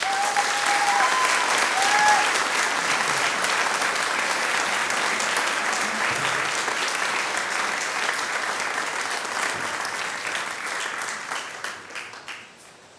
Applause 1
applaud applauding applause audience auditorium cheer cheering clap sound effect free sound royalty free Sound Effects